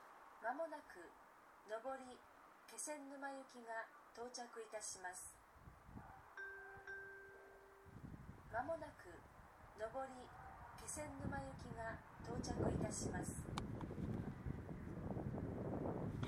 この駅では接近放送が設置されています。
接近放送普通　気仙沼行き接近放送です。